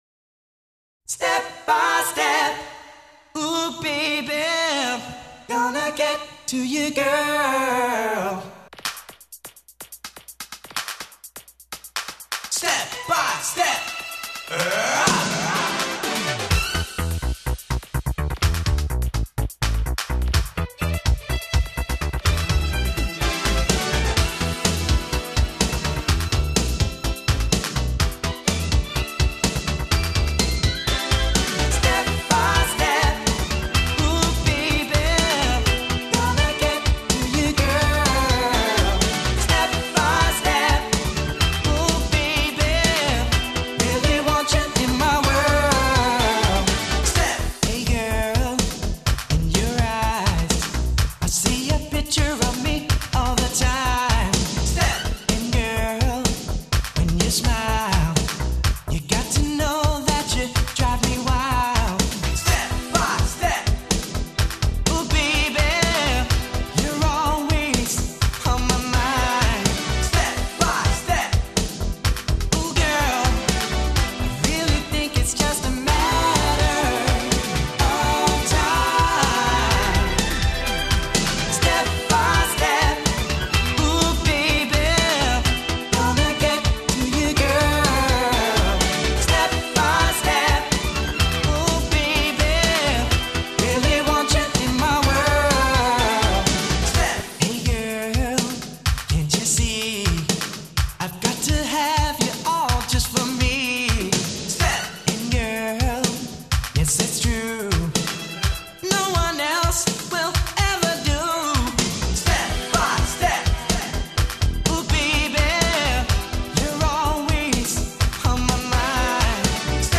很久以前的美国男子组合。